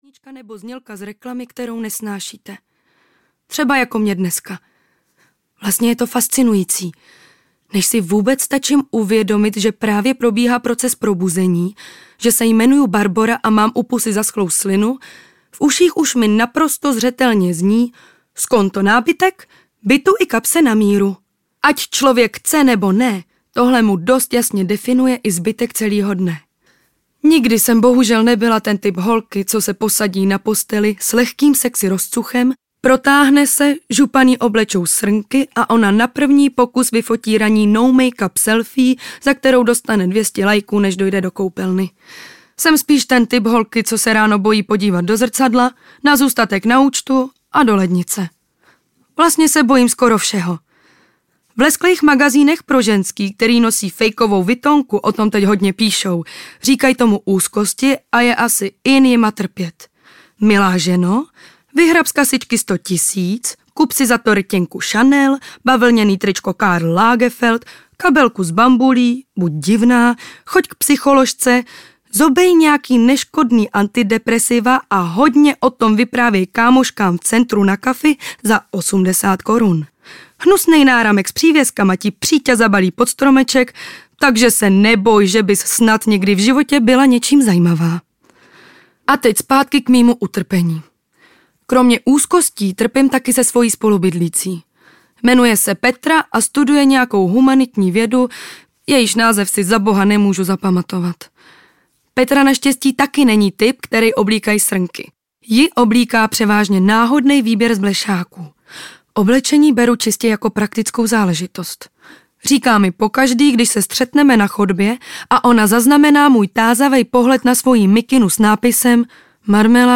Losos v kaluži audiokniha
Ukázka z knihy